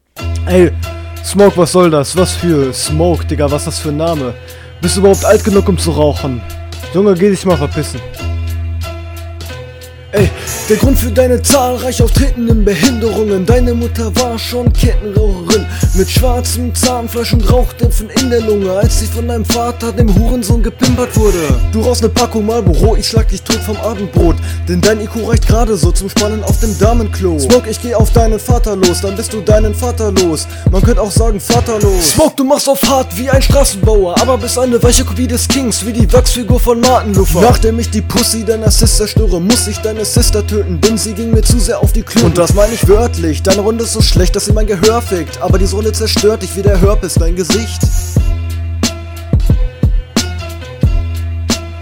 bist öfters offbeat